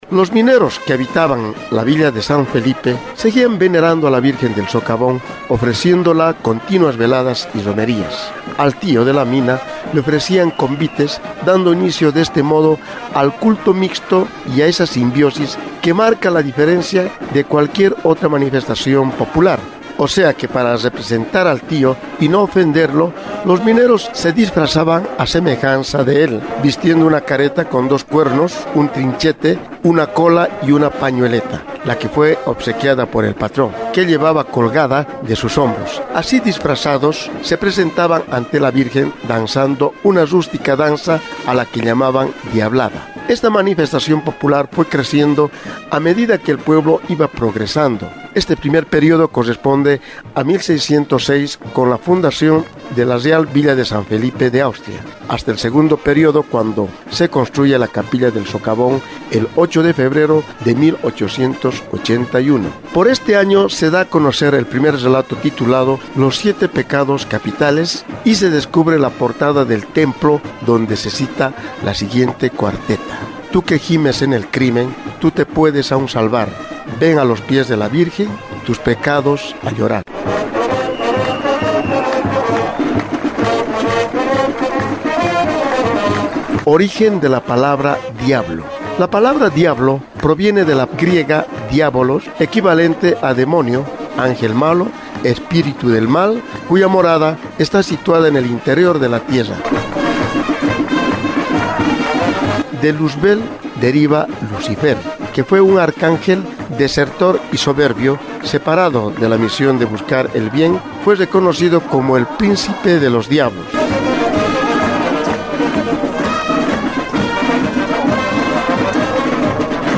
En cuanto a la música, esta danza que inicialmente era acompañada por instrumentos de viento y percusión originarios, ahora cuenta con el marco musical de una banda de músicos.